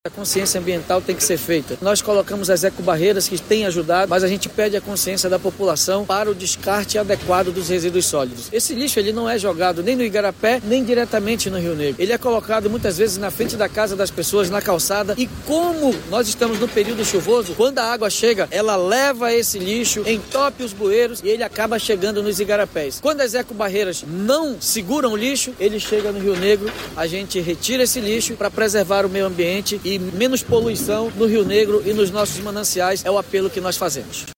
O prefeito de Manaus, Davi Almeida, ressalta que 14 ecobarreiras foram implantadas, em Manaus, mas que o descarte incorreto de lixo ainda é a principal causa de poluição do Meio Ambiente.